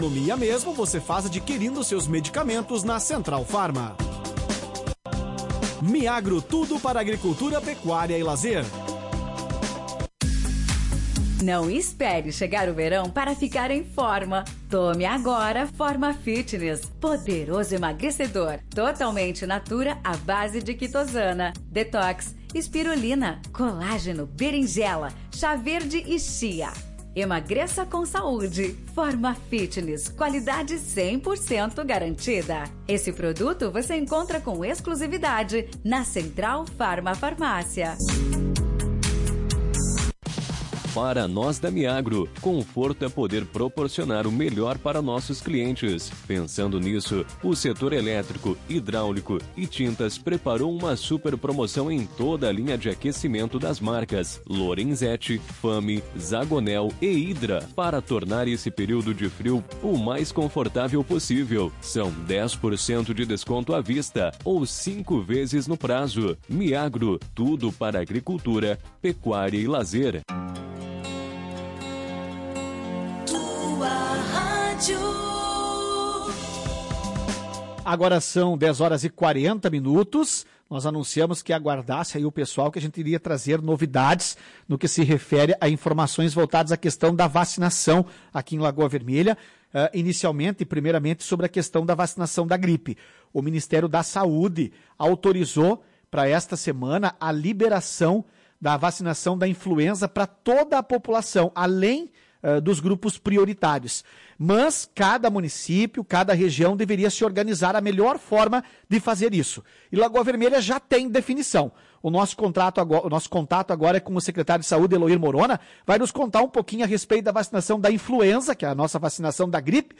O secretário de Saúde Eloir Morona informou na manhã desta sexta-feira, 09 de julho, que as vacinas contra a Influenza (gripe) serão disponibilizadas para toda a população a partir desta segunda-feira, 12 de julho.